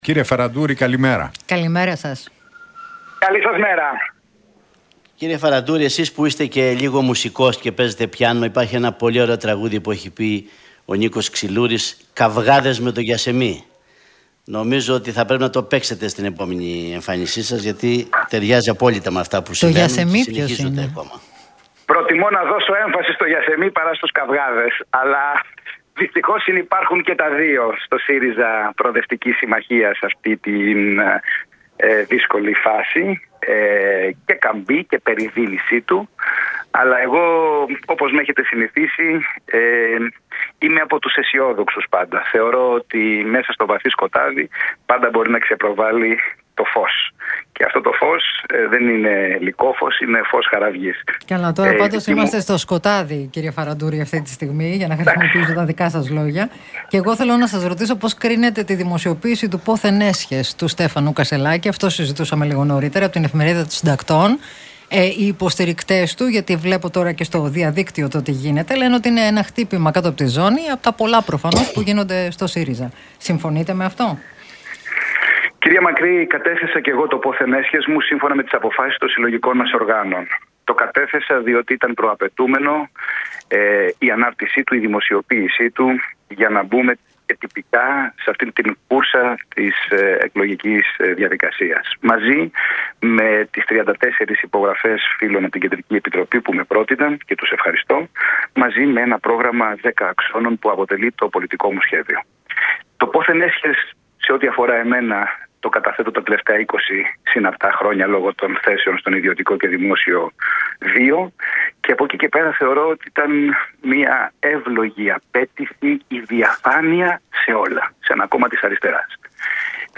Για τις τελευταίες εξελίξεις στον ΣΥΡΙΖΑ, το Πόθεν Έσχες Κασσελάκη αλλά και τις δημοσκοπήσεις μίλησε ο υποψήφιος πρόεδρος του ΣΥΡΙΖΑ, Νικόλας Φαραντούρης